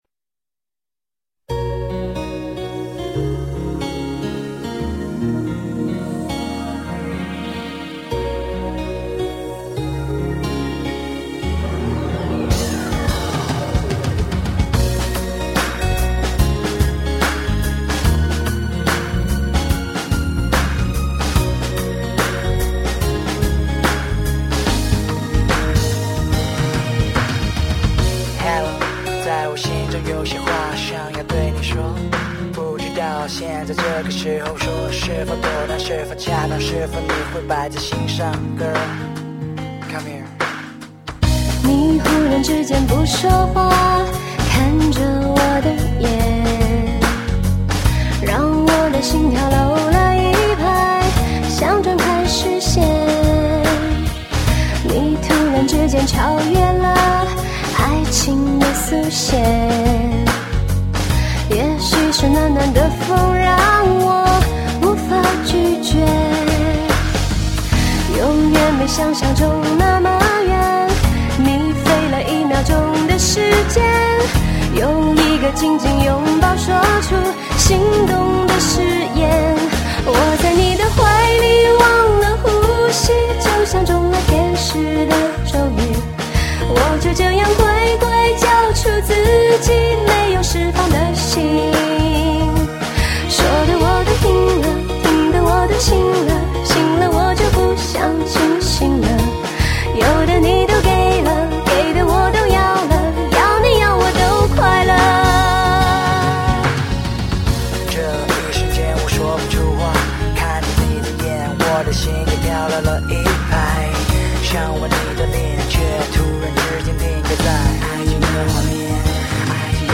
歌手类别：大陆女歌手